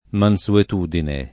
[ man S uet 2 dine ]